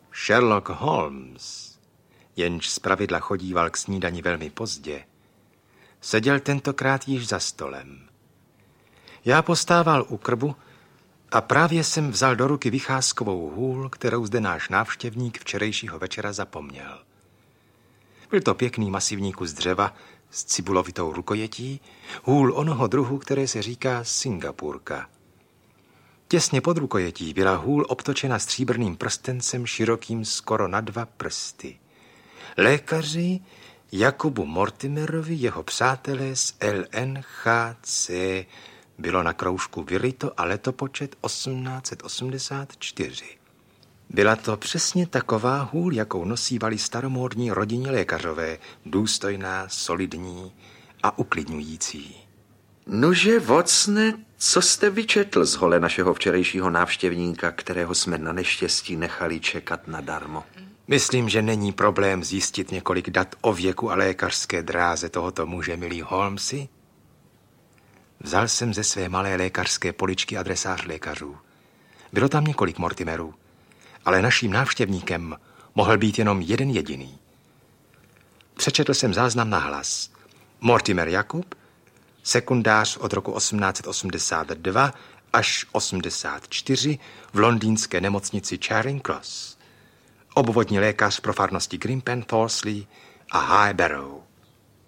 Pes baskervillský audiokniha
Ukázka z knihy
Ve zvukové verzi zdramatizované slavné anglické detektivky zvláště vynikne napětí, smíšené s ironií, se kterou autor románu, sir A.C. Doyle, koncipoval genialitu svého detektiva. Otakar Brousek v roli Sherlocka Holmese a Josef Červinka jako vypravěč dotvářejí magickou atmosféru postupně odhalovaných stop zločinu, který se odehrál v bažinách prokletého hrabství.